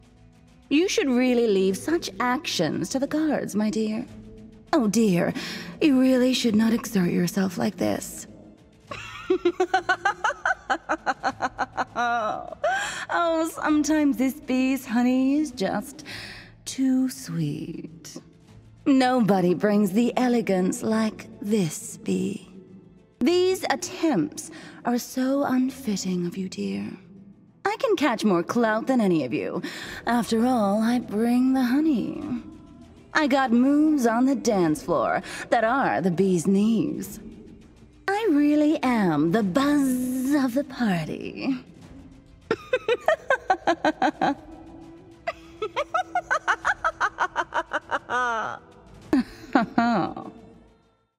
Young Adult, Adult, Teenager, Child, Mature Adult
Has Own Studio